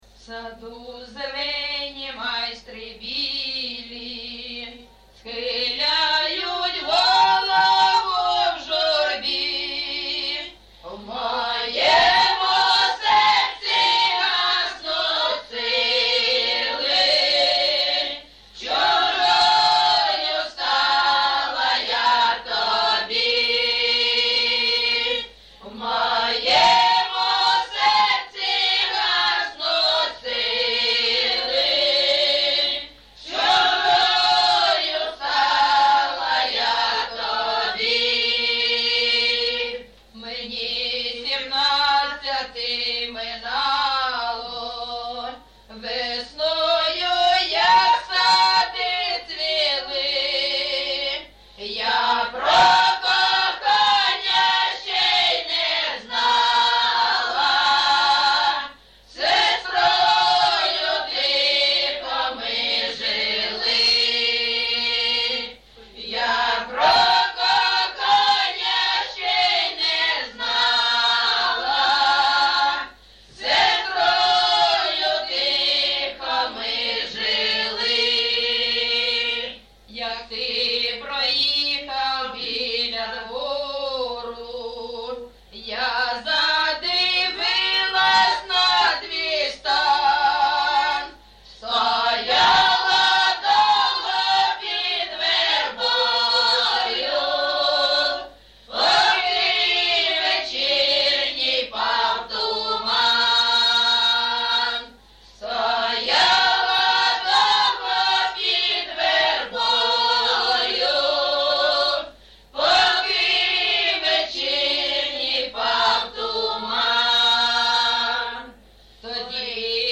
ЖанрРоманси, Пісні літературного походження
Місце записус-ще Щербинівка, Бахмутський район, Донецька обл., Україна, Слобожанщина